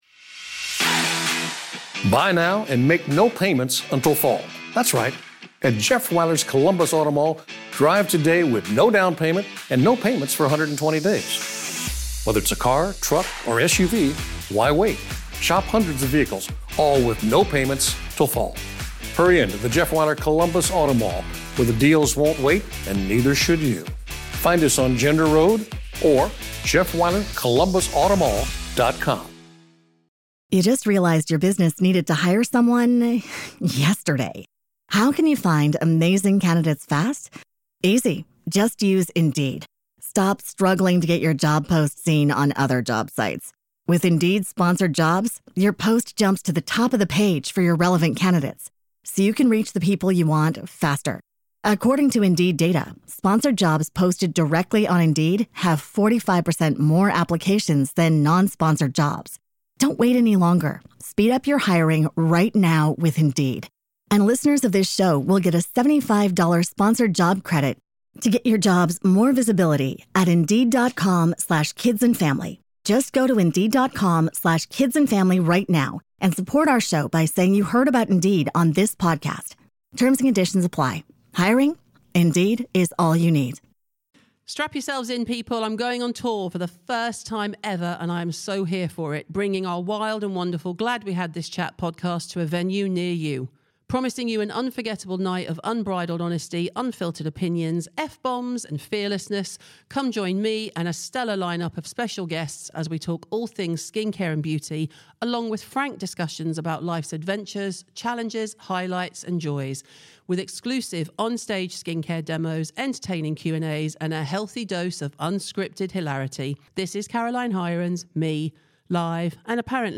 Presented By: Caroline Hirons Ad Details: Glad We Had This Chat Tour: I’m going on TOUR for the first time ever, and I’m here for it—bringing my wild and wonderful Glad We Had This Chat podcast to a venue near you.